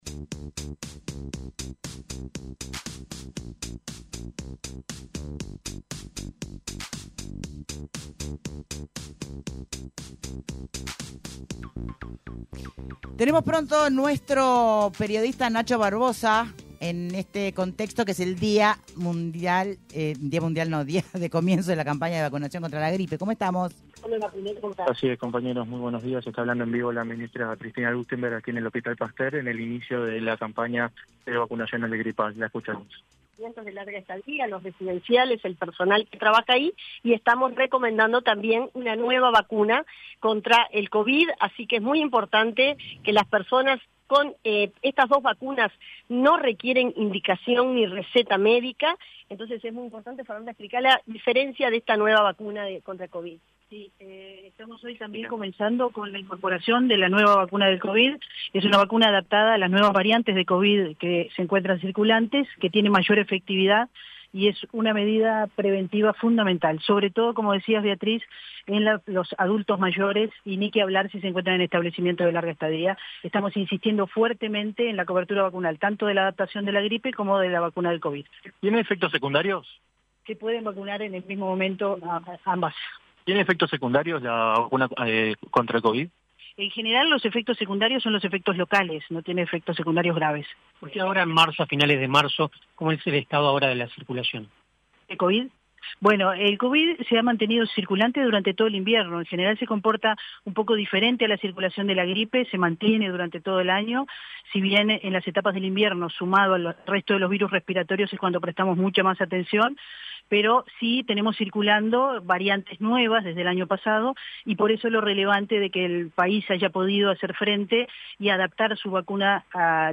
La directora de Cultura de la Intendencia de Montevideo, Débora Quiring, en diálogo con Punto de Encuentro en Radio Universal, destacó la variedad de propuestas para todas las edades, y brindó los detalles de la semana.